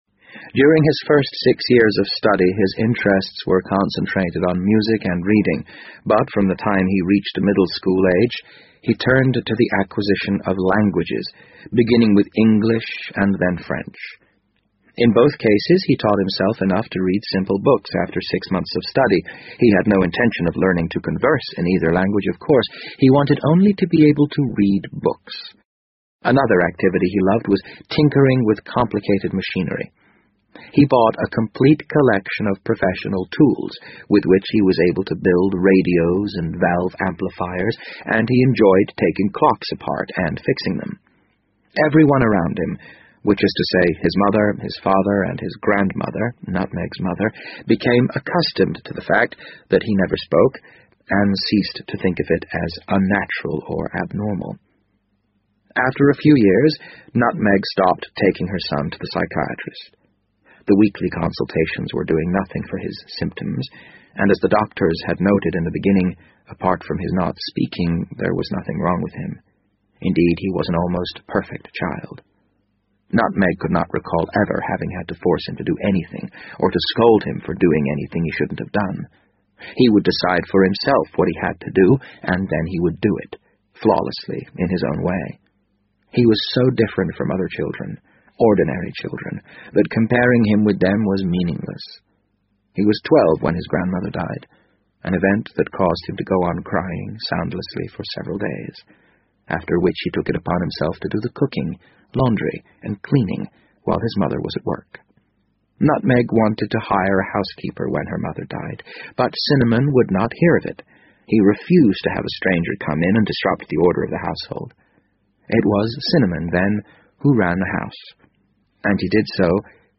BBC英文广播剧在线听 The Wind Up Bird 011 - 13 听力文件下载—在线英语听力室